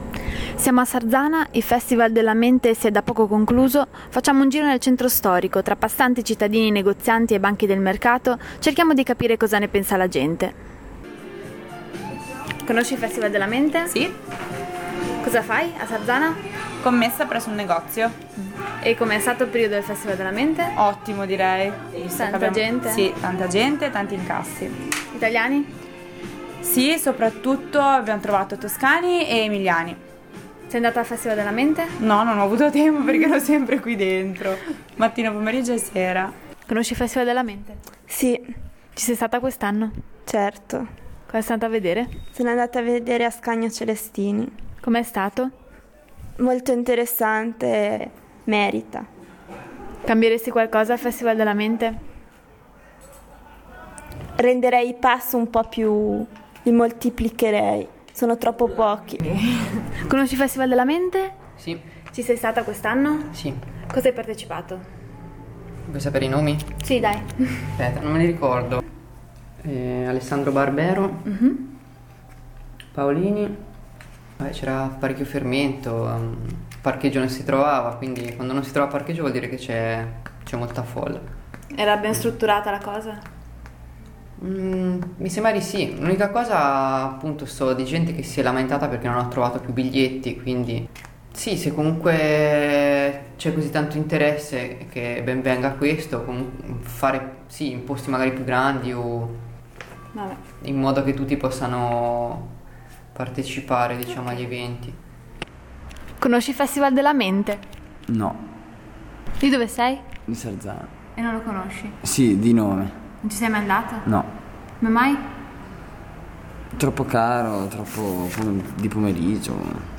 Intervista a VOX POPULI